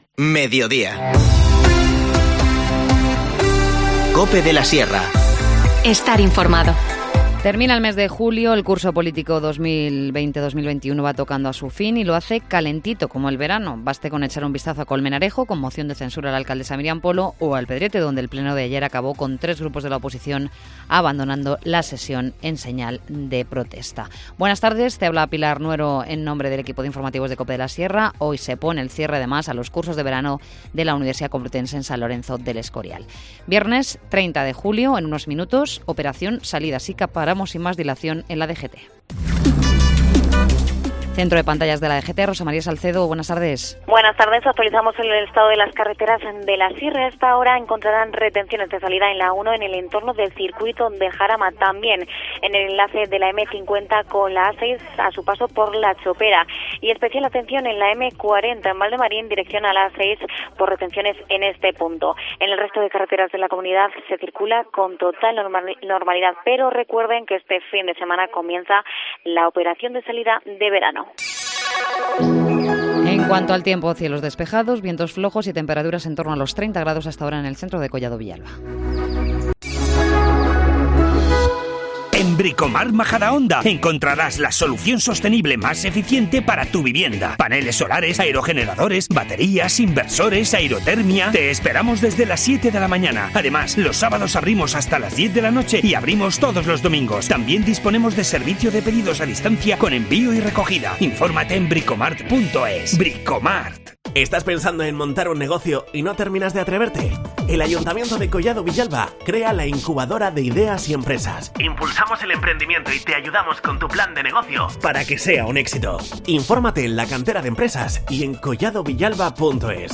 Informativo Mediodía 30 julio